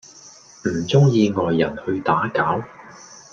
Голоса - Гонконгский 430